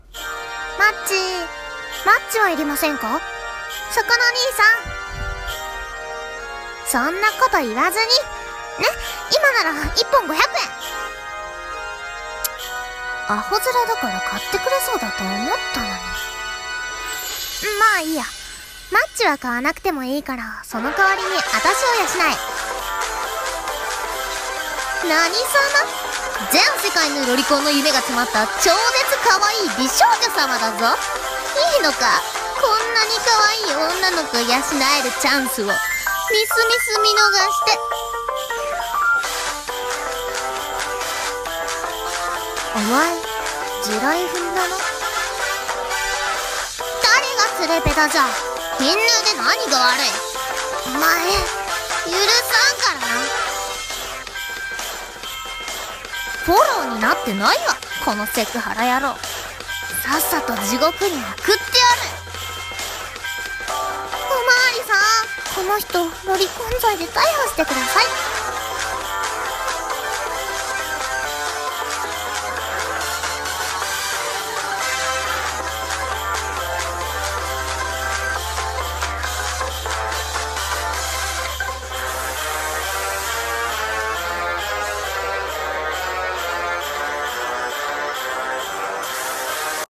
こんなマッチ売りの少女はイヤだ【声劇台本】